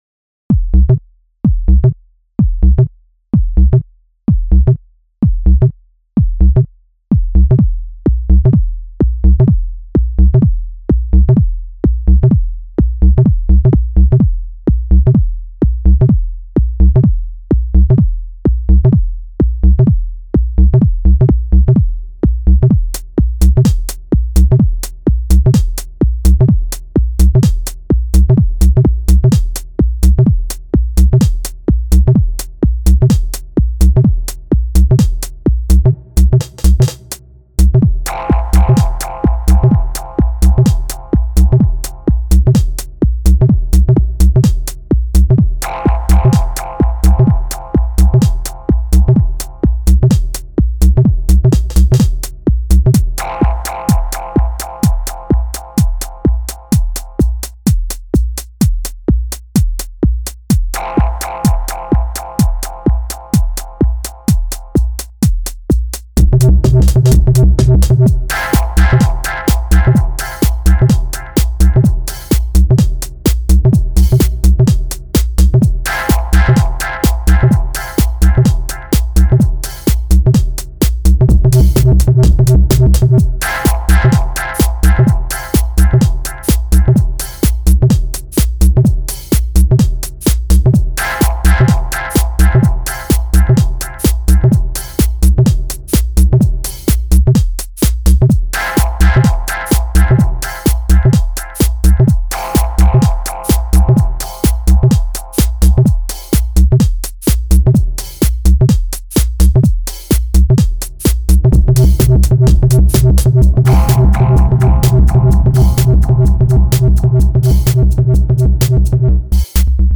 Genre: Minimal / Electro